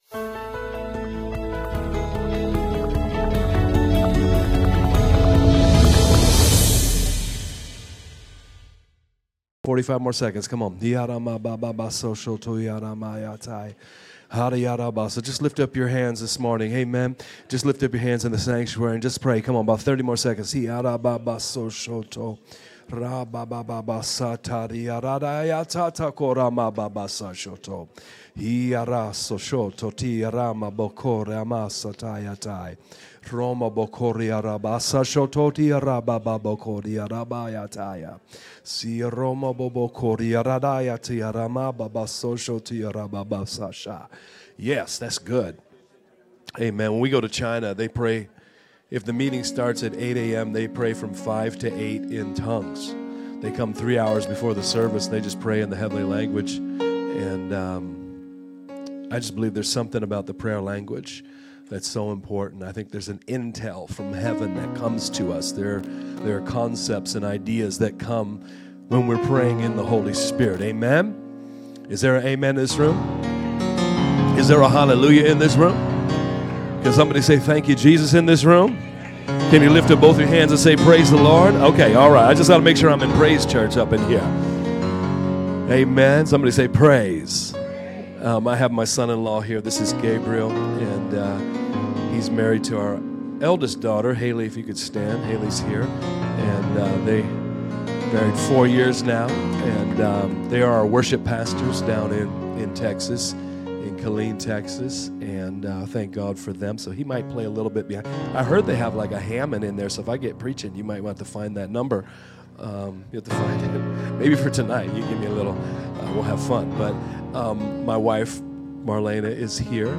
Sermons | Praise Church of New Orleans